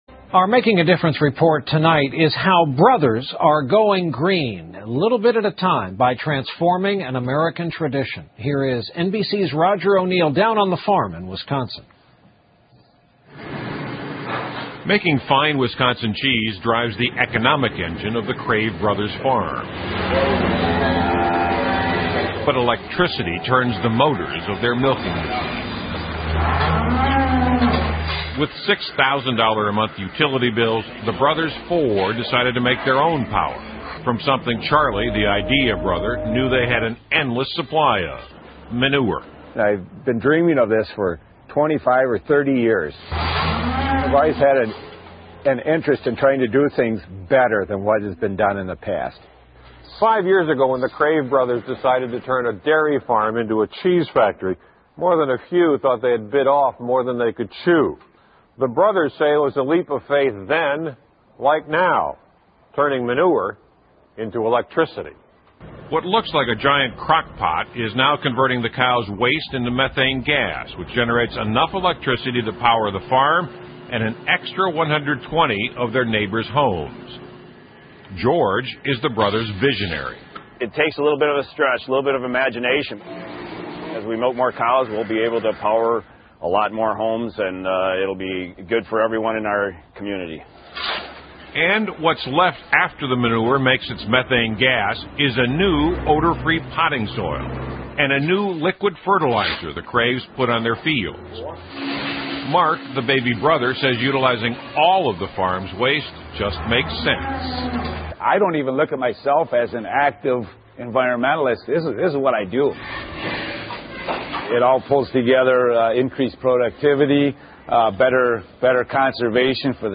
访谈录 2008-04-13&4-15 芝士工厂的绿色农副产品 听力文件下载—在线英语听力室